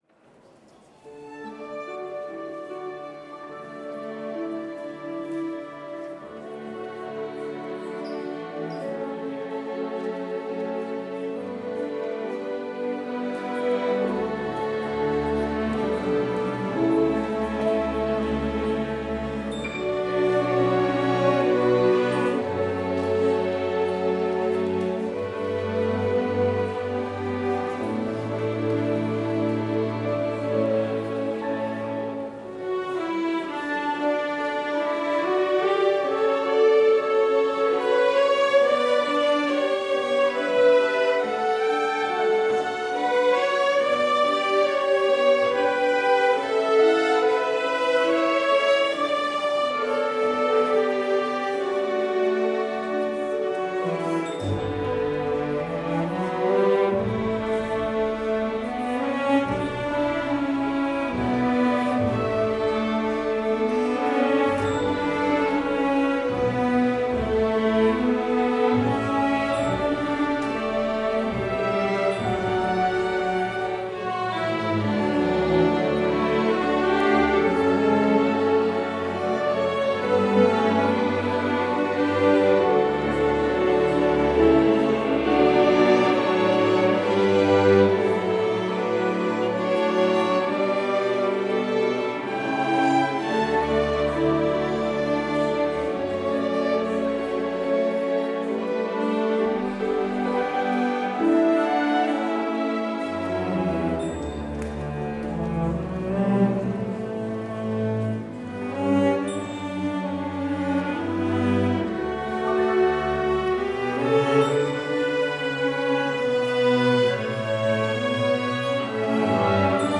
Complete service audio for Chapel - Friday, November 22, 2024